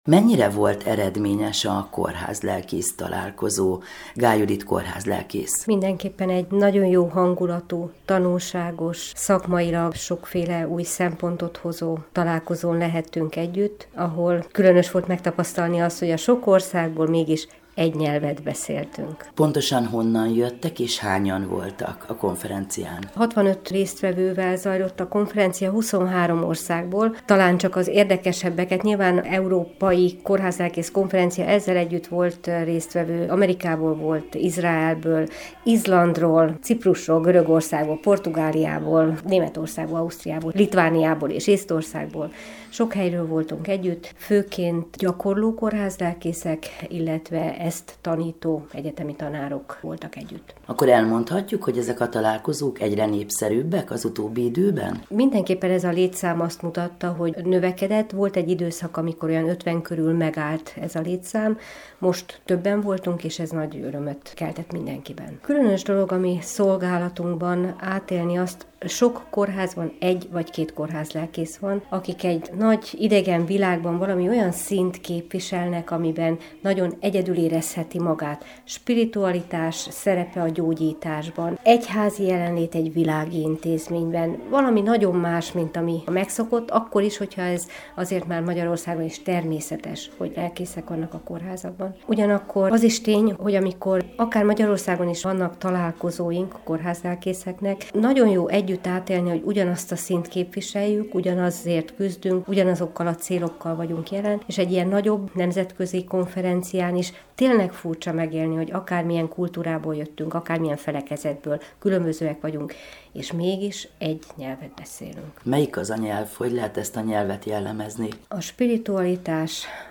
Interjú: